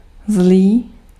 Ääntäminen
IPA : /ˈbæd/